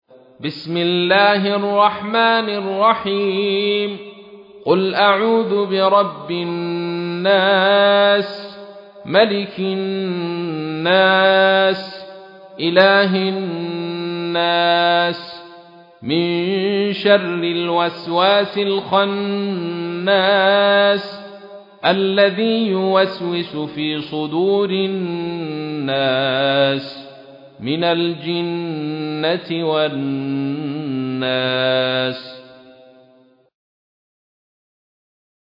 تحميل : 114. سورة الناس / القارئ عبد الرشيد صوفي / القرآن الكريم / موقع يا حسين